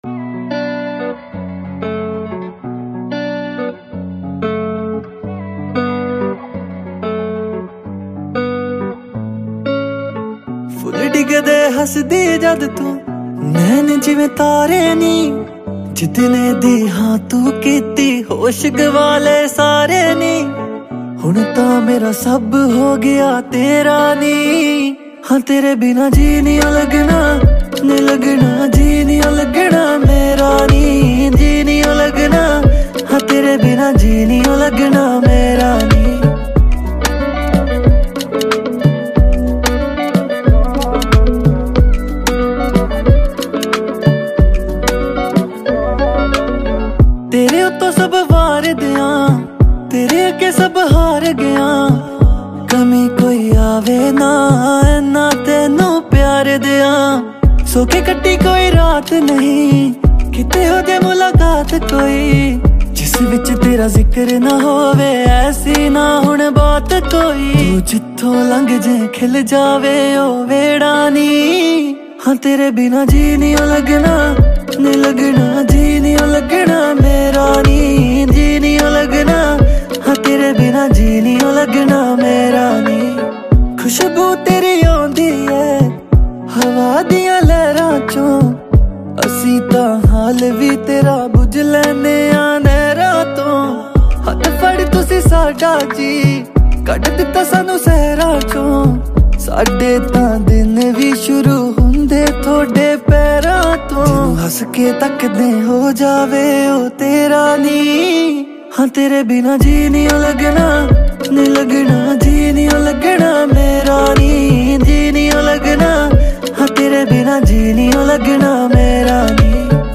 a romantic Punjabi song
Punjabi Songs